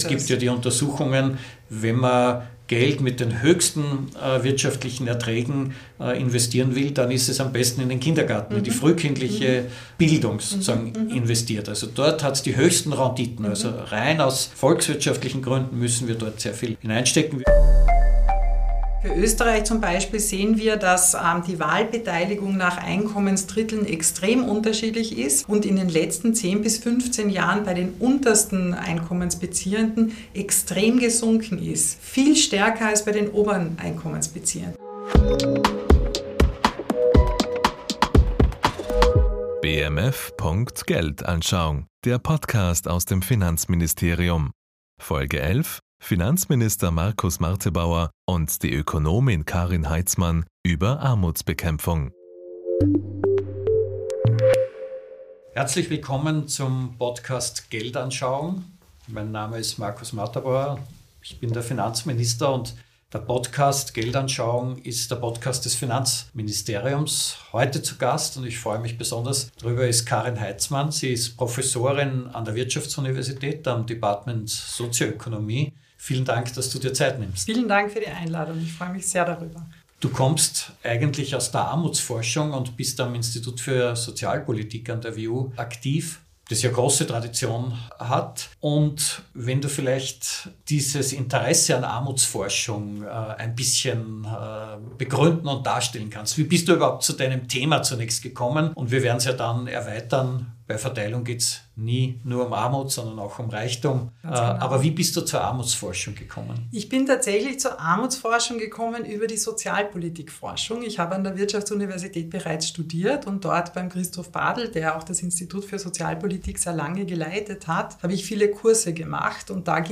Was sind die Ursachen von Armut – und wie lassen sie sich bekämpfen? Ein Gespräch über strukturelle Probleme, die Rolle des Sozialstaats, Wissenslücken beim Thema Reichtum und darüber, welche Folgen Armut für unsere Demokratie hat.